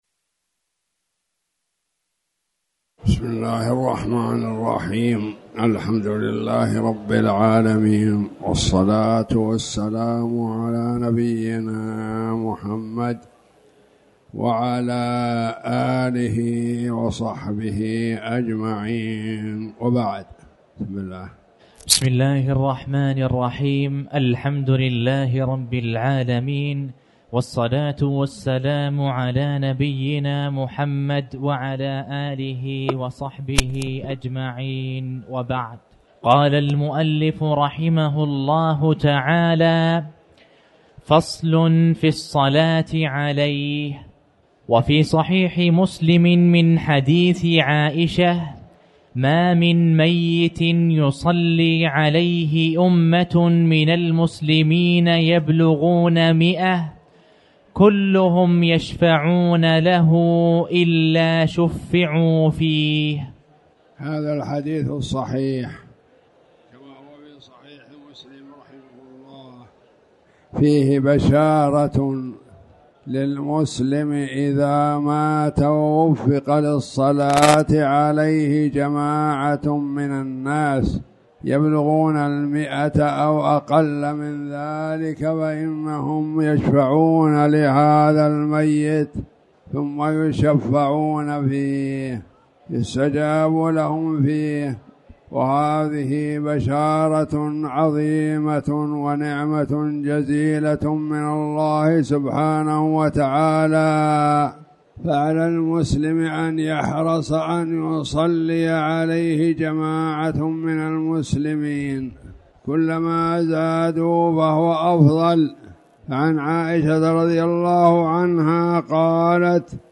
تاريخ النشر ٢٢ صفر ١٤٣٩ هـ المكان: المسجد الحرام الشيخ